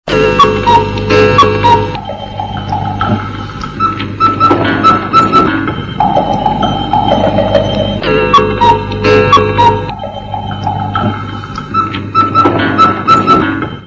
Cine y Televisión / Efectos de sonido